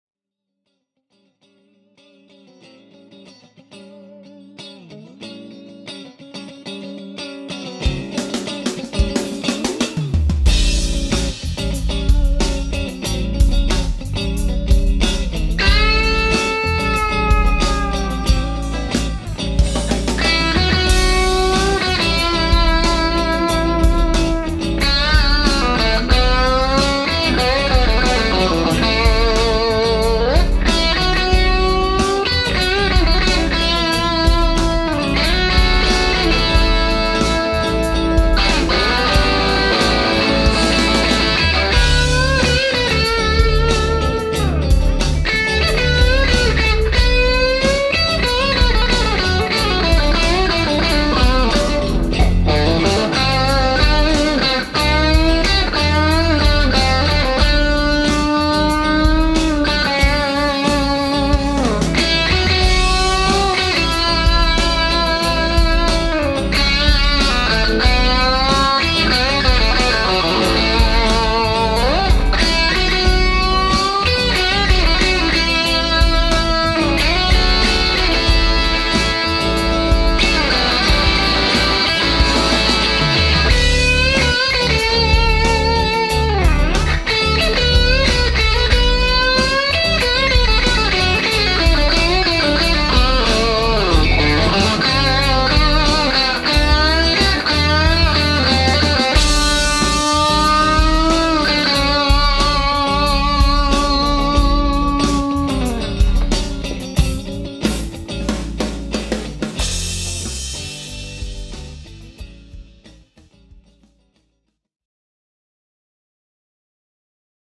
In verse 1, I’m playing the PLX18BB with nothing added – just a touch of room reverb as an insert in the mix. In the second verse, I switch on the KASHA overdrive (still with some reverb in the mix). The pedal is in the “Hot” channel, and I set the gain knob at 12 o’clock, which just provides a bit of a gain boost (it’s capable of adding up to 15dB of boost in this channel), but this channel also sustains for days, adding a touch of high-end sparkle.
Please excuse the little playing mistakes I made… 🙂 It’s nothing really egregious. In any case, when you compare the two verses, the difference in tone is actually subtle, at least to my ears.